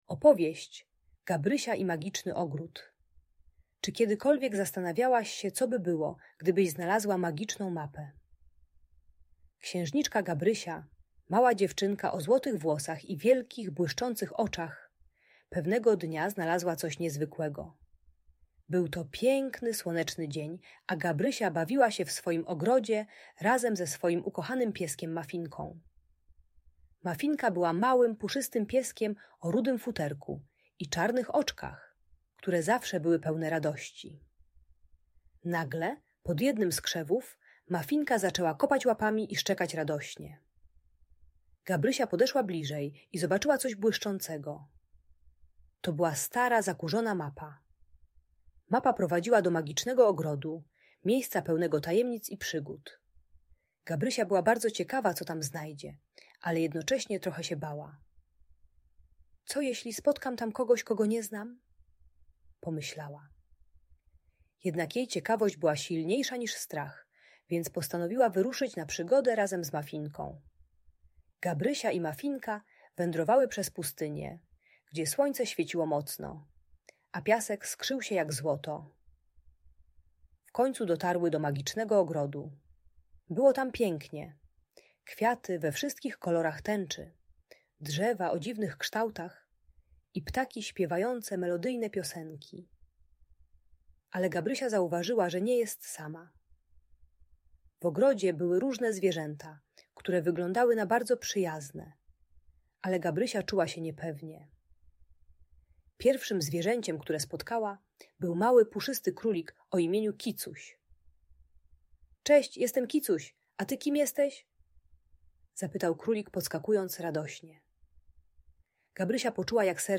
Opowieść o Gabrysi i Magicznym Ogrodzie - Audiobajka